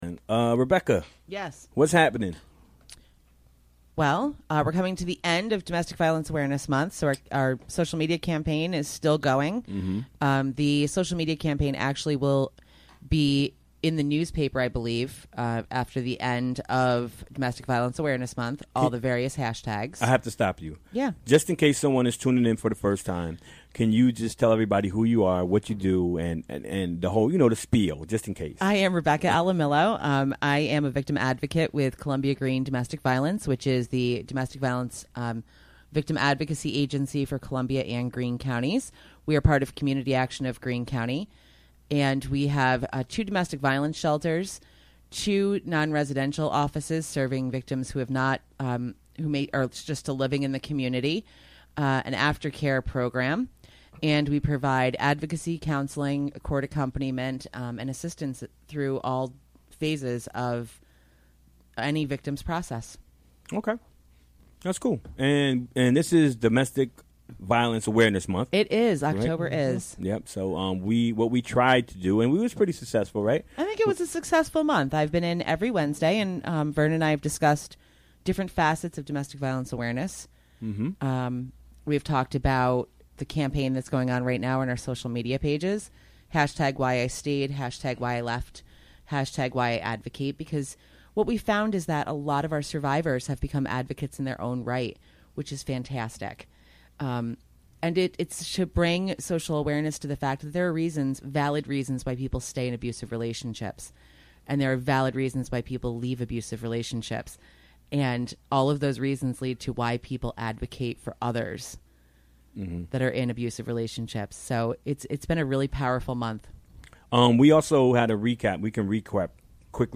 Recorded during the WGXC Afternoon Show Wednesday, October 25, 2017.